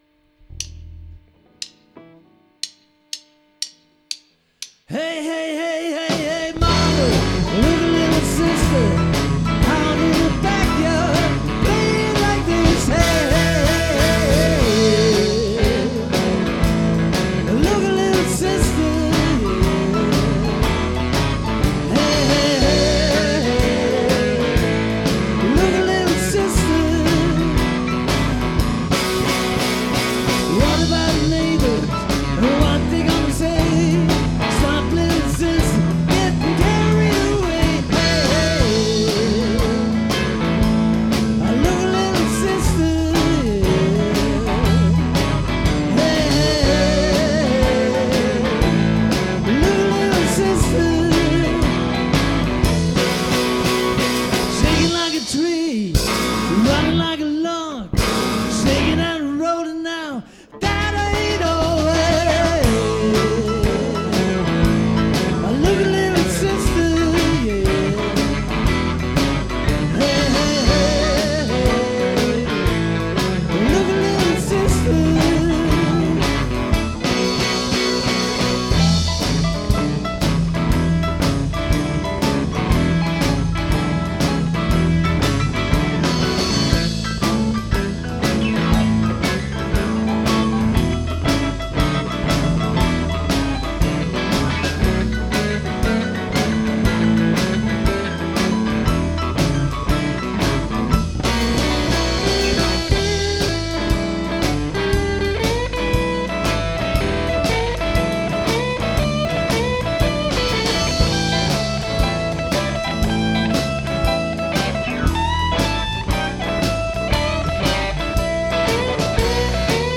ELECTRIC SET
Die Aufnahmen wurden während einer regulären Probe gemacht